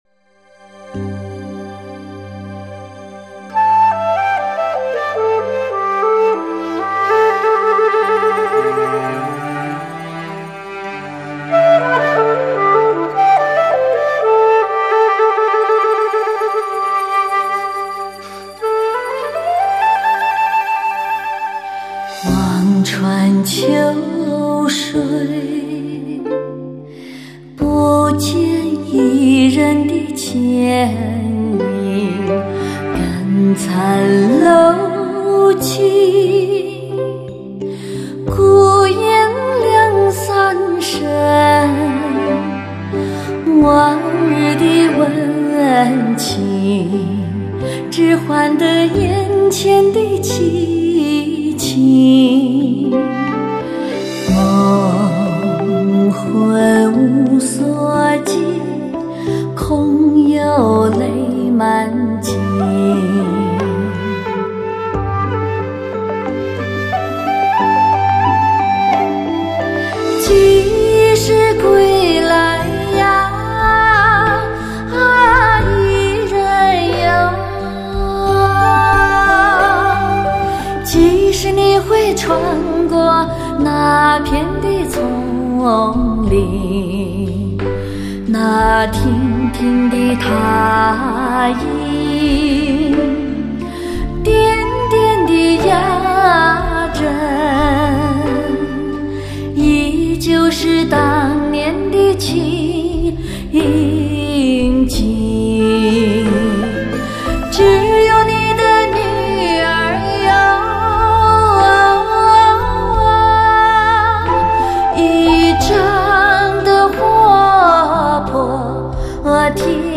醉人的嗓音唤醒你那沉睡已久的耳膜。